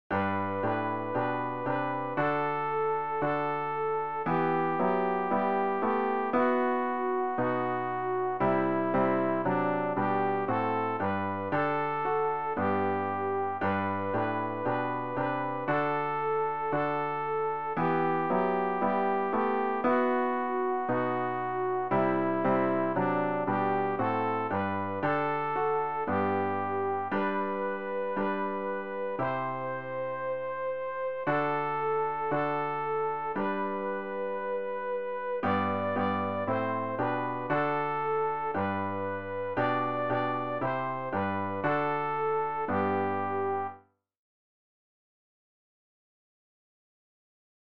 sopran-rg-161-wunderbarer-koenig.mp3